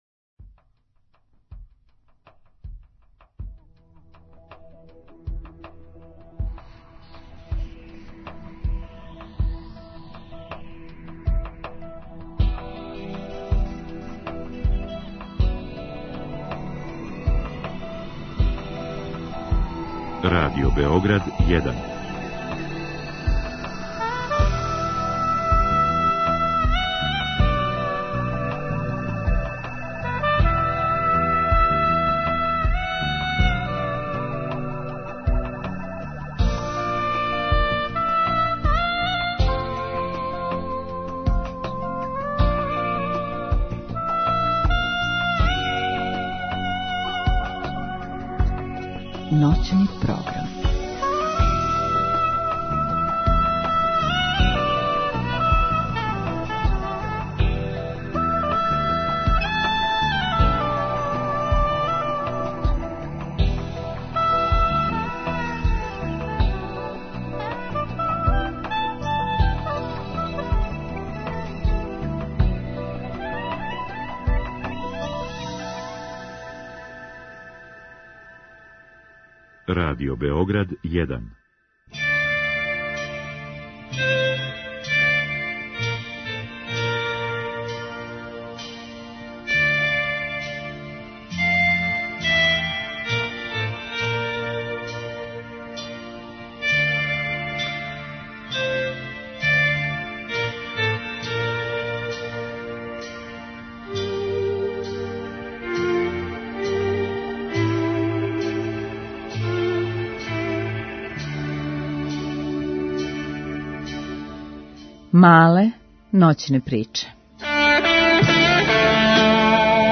Гост: Ивица Краљ